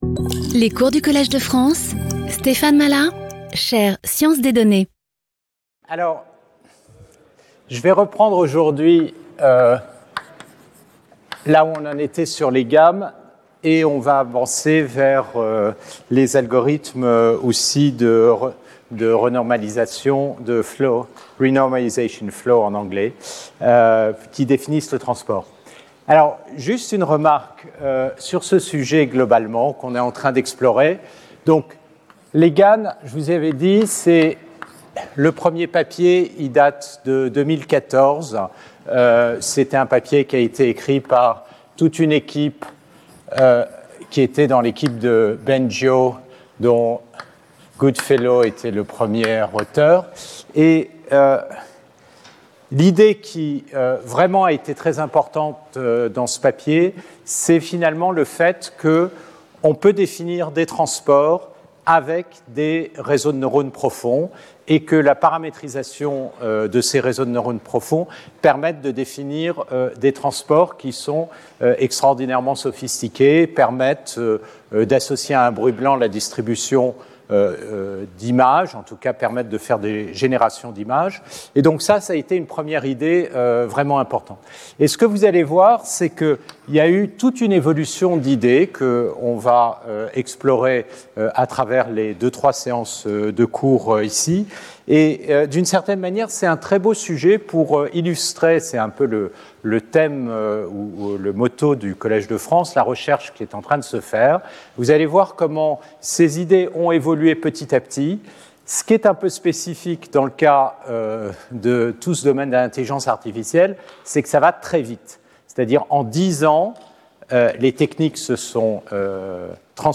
Speaker(s) Stéphane Mallat Professor at the Collège de France
Lecture